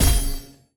etfx_explosion_flash.wav